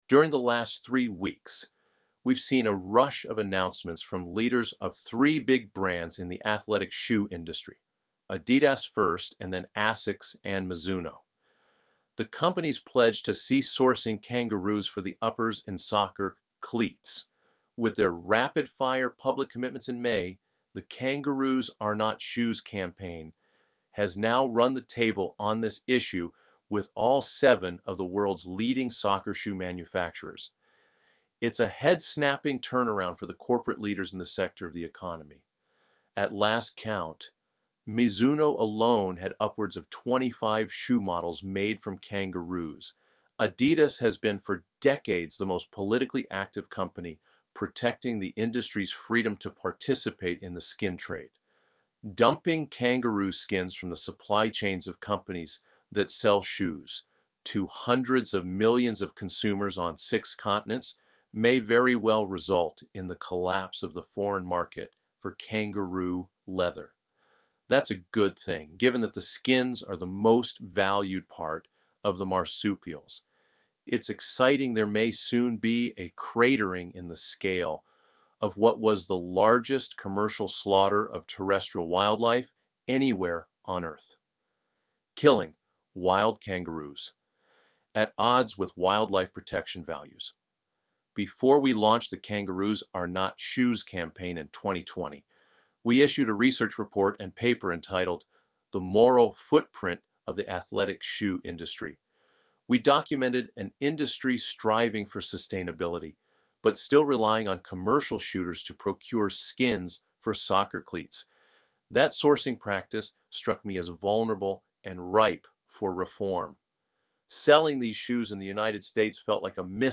We’ve set off a corporate stampede away from kangaroo skins as soccer shoe casing You can listen to an AI-generated reading of this story here: During the last three weeks, we’ve seen a rush of announcements from leaders of three big brands in the athletic shoe industry — Adidas first and then ASICS and Mizuno.